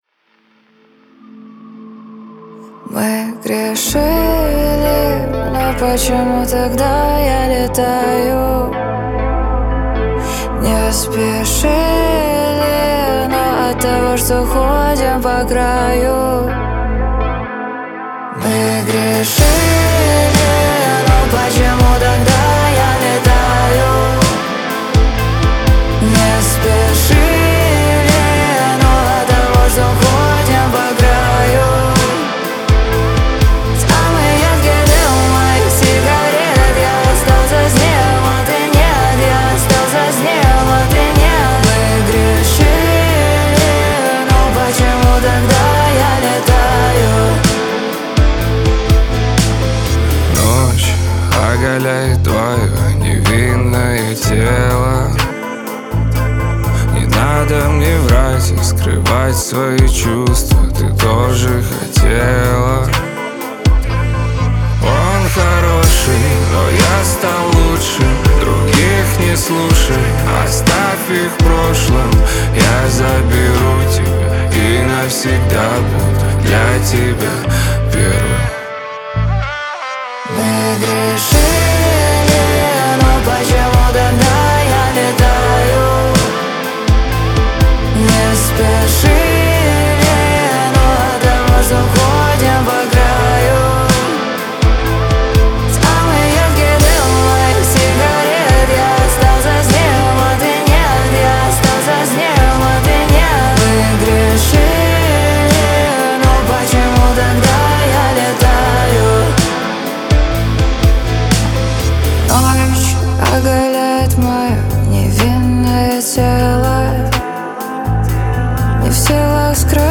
Жанр: Новинки русской музыки